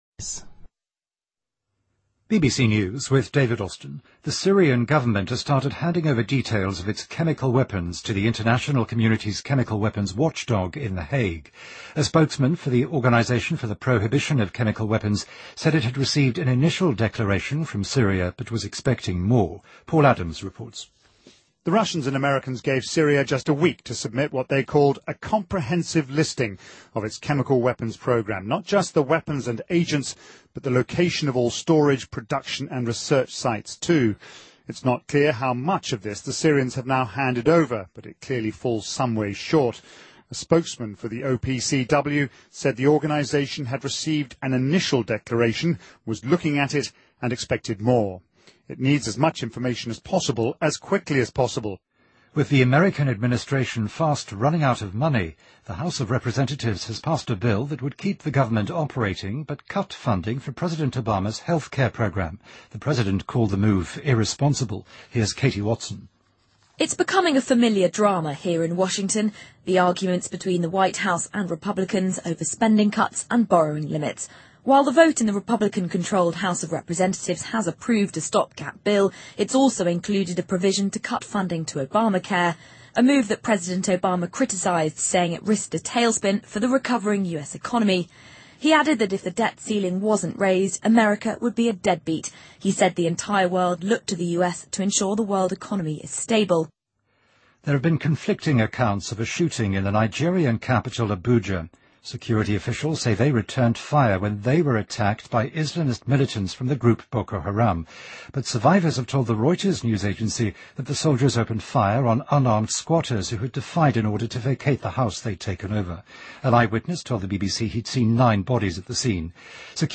BBC news,加拿大智能手机制造商黑莓公司将削减全球40%的员工